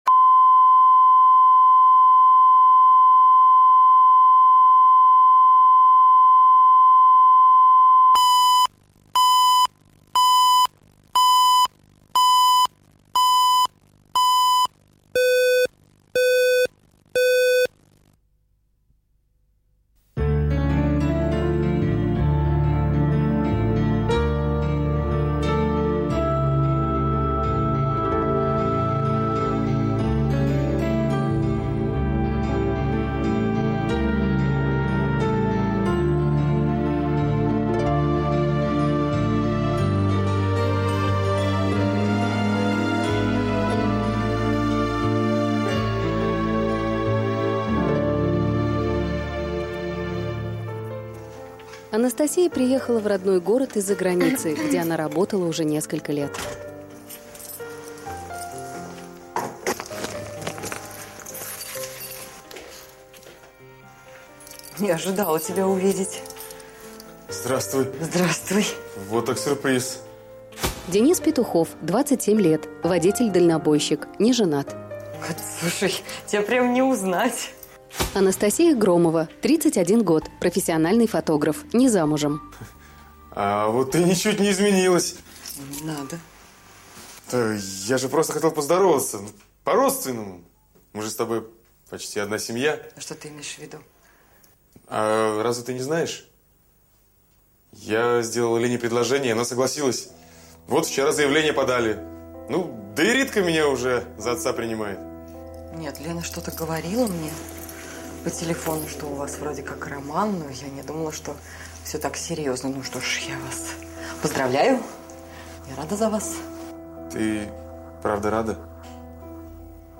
Аудиокнига Надежный тыл | Библиотека аудиокниг